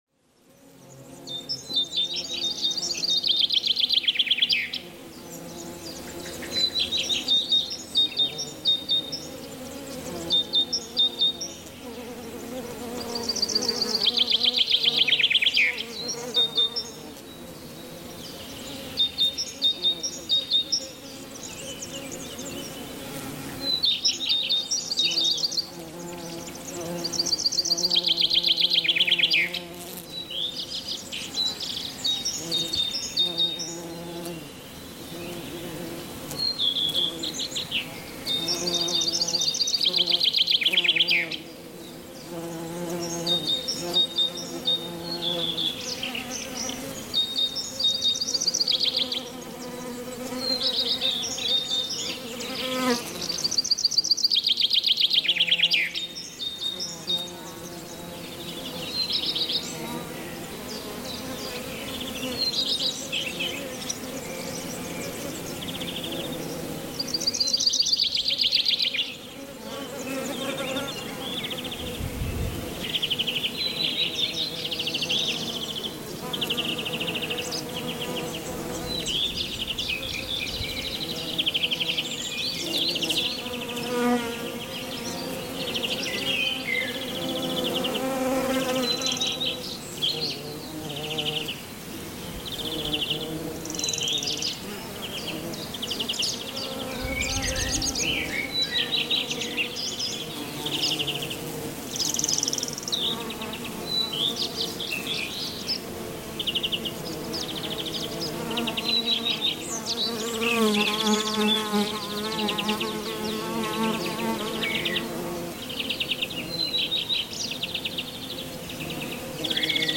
NATÜRLICHER ENERGIE-BOOSTER: Bienen-Power mit Sonnenschein-Summen